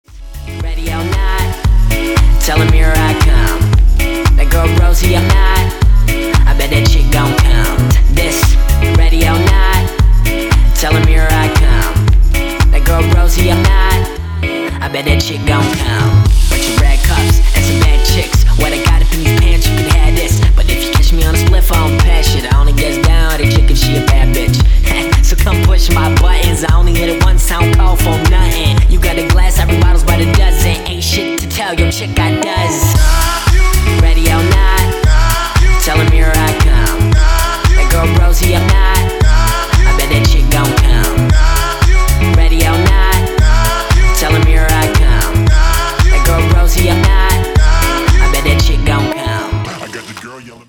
мужской вокал
зажигательные
dance
indie pop